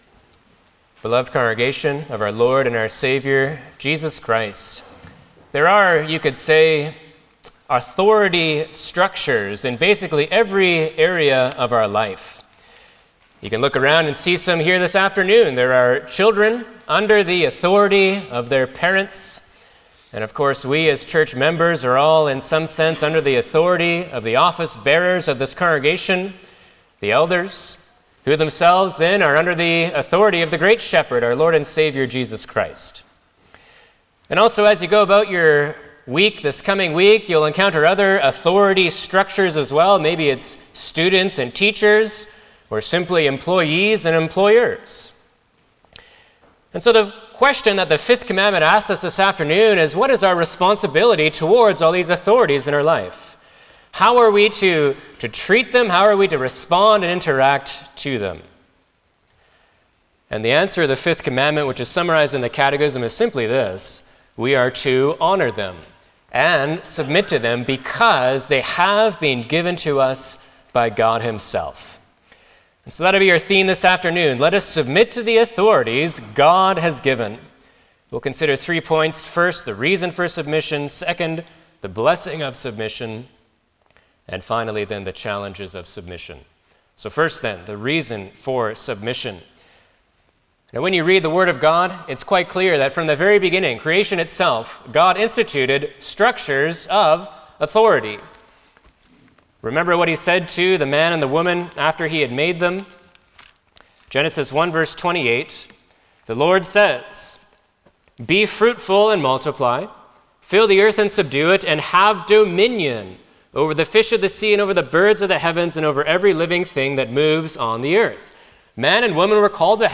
Service Type: Sunday afternoon
07-Sermon.mp3